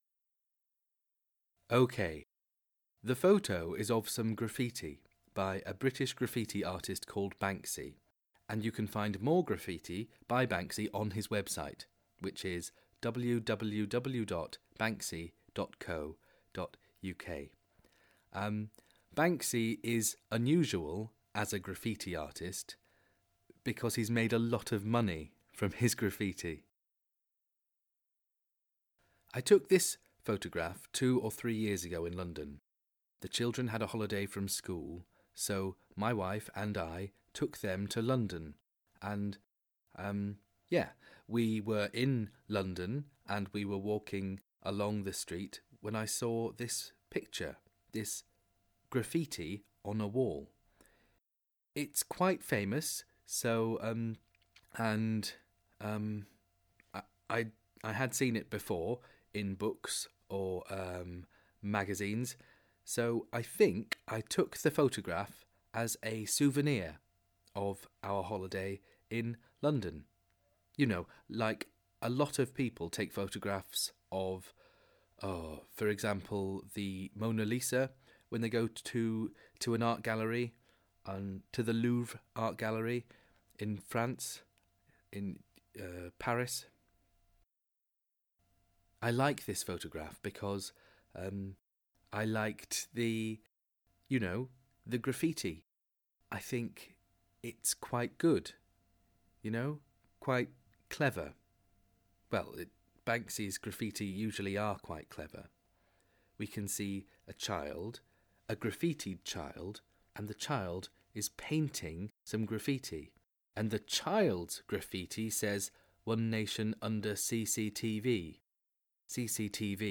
IntroductionIn this lesson students listen to a man talking about a photograph.